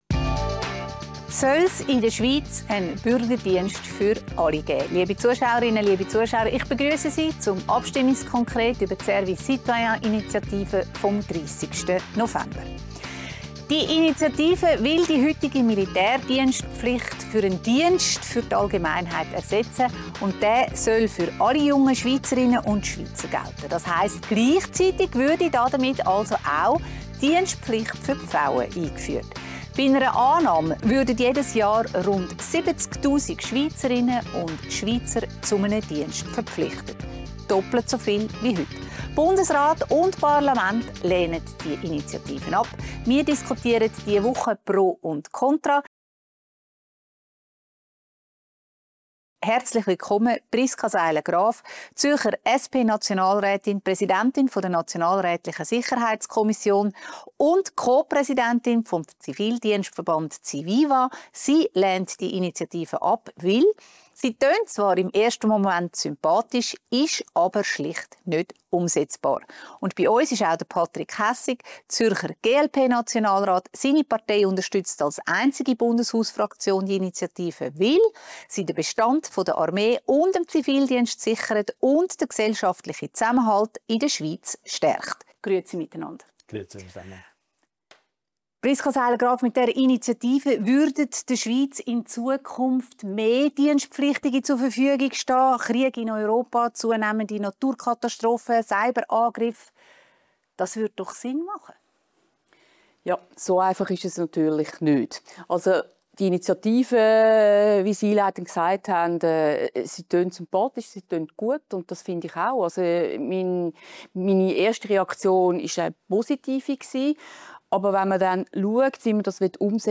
Zu Gast sind die beiden Zürcher Nationalräte Priska Seiler Graf, SP und Patrick Hässig, GLP Mehr